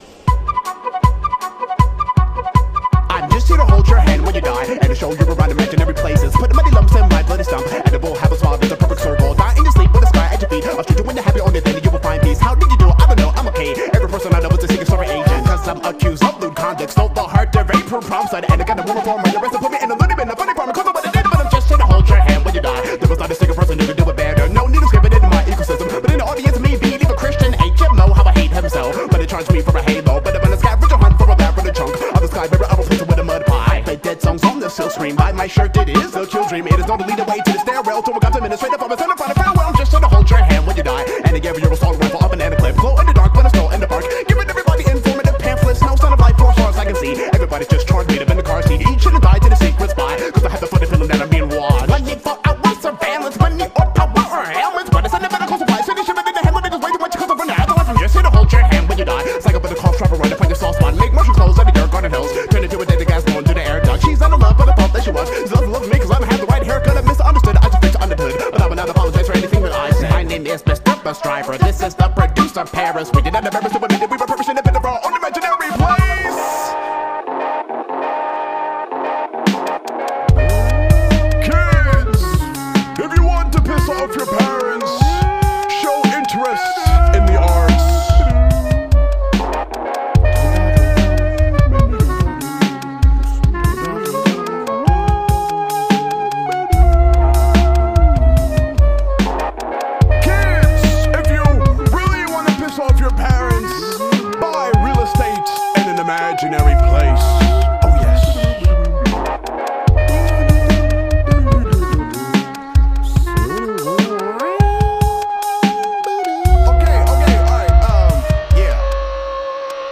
BPM158-163
Audio QualityCut From Video